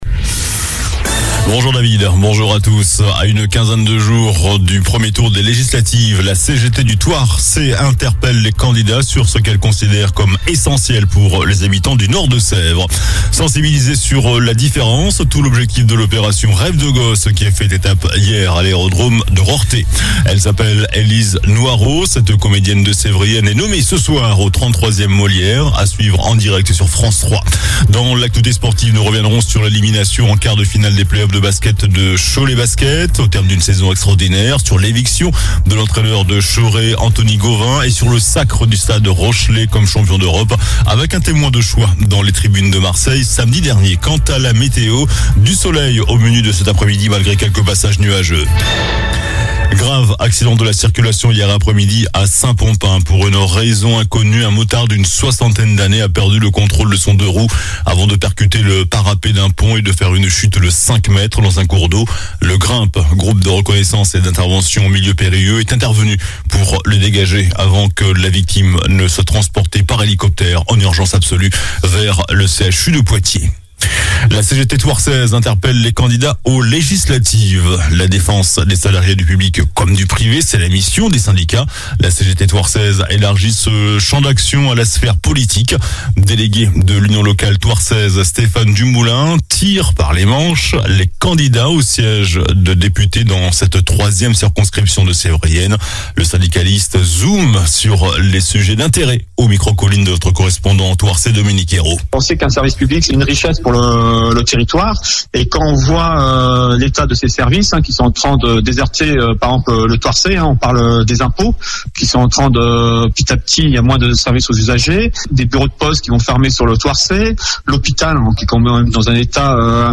JOURNAL DU LUNDI 30 MAI ( MIDI )